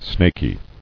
[snak·y]